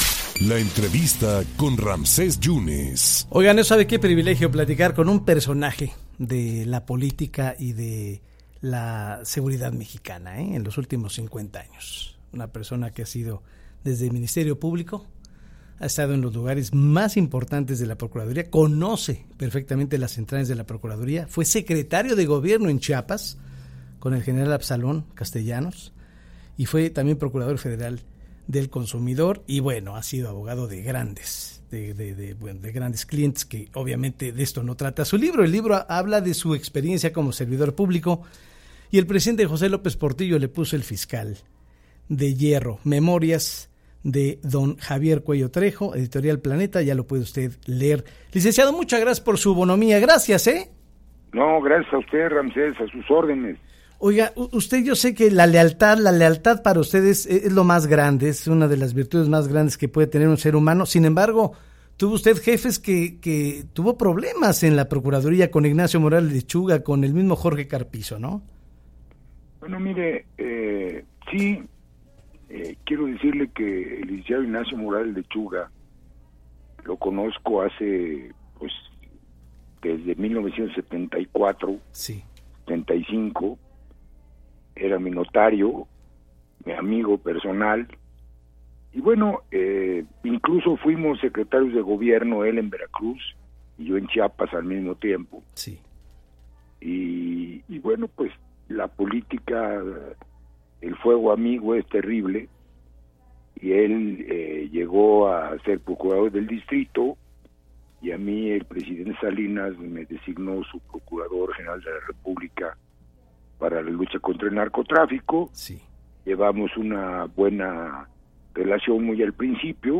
Redacción/Xalapa.- El abogado y político Javier Coello Trejo, platicó para En Contacto sobre su reciente libro donde habla de sus memorias.